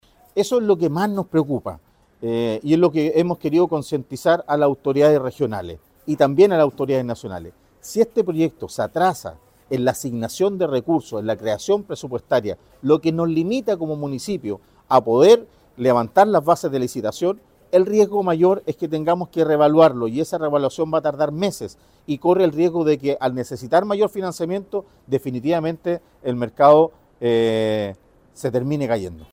El alcalde de la ciudad chorera comentó que su “mayor temor es que tengamos que reevaluar” el proyecto de inversión para la recuperación del Mercado.
Mercado-Talcahuano-2-Alcalde-Talcahuano.mp3